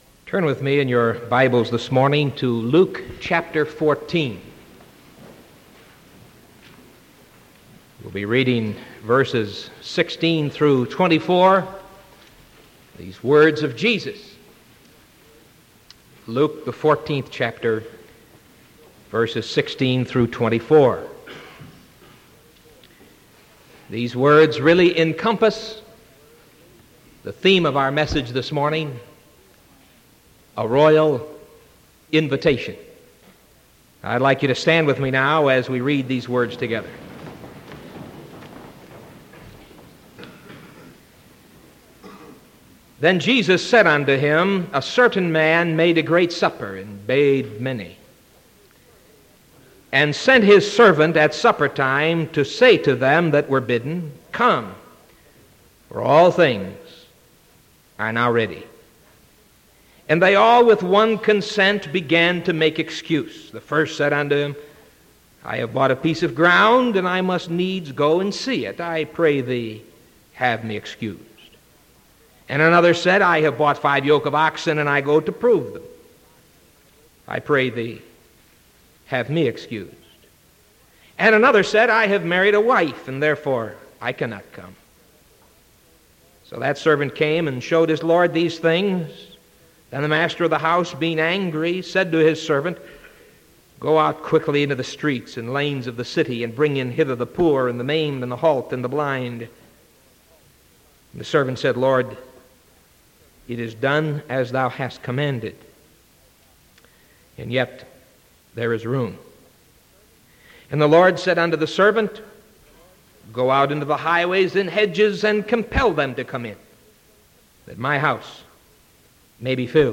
Sermon August 31st 1975 AM